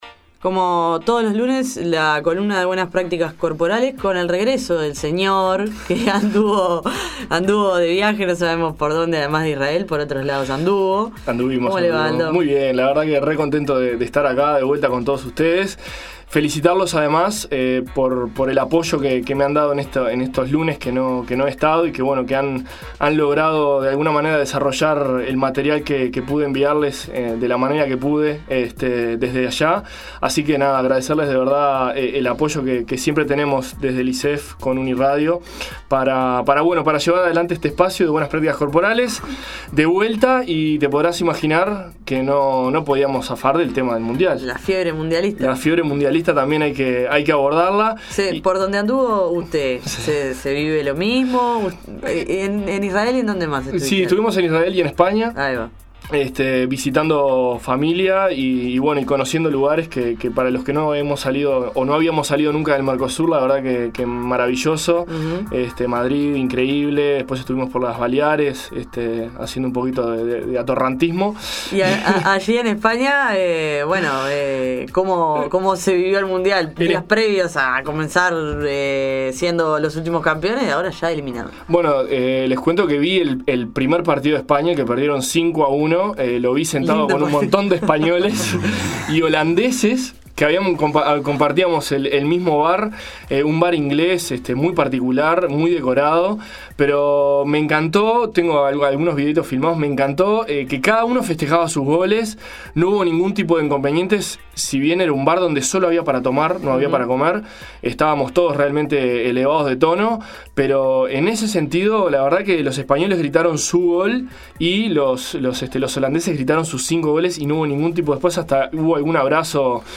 Columna de Buenas Prácticas Corporales de La Nueva Mañana, en el marco del Proyecto de Extensión del Instituto Superior de Educación Física (ISEF).